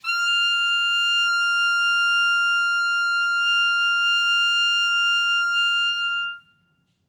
Clarinet
DCClar_susLong_F#5_v2_rr1_sum.wav